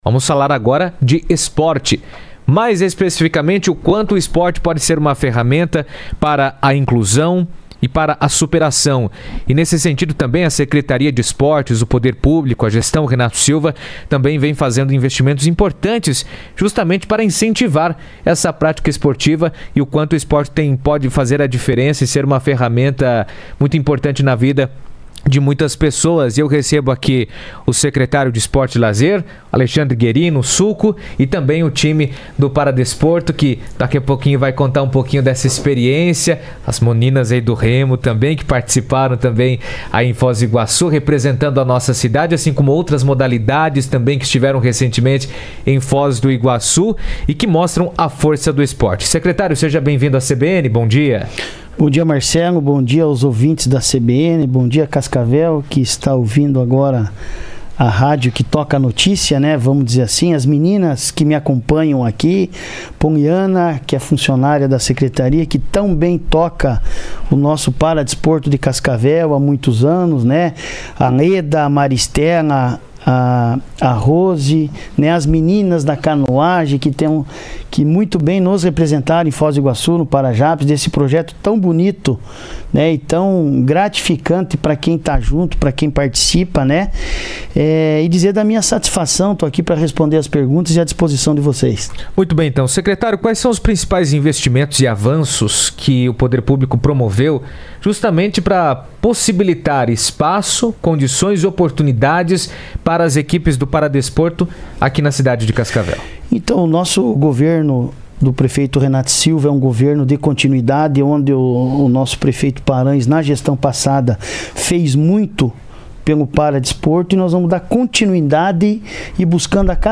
12:00 Ouça a reportagem Foto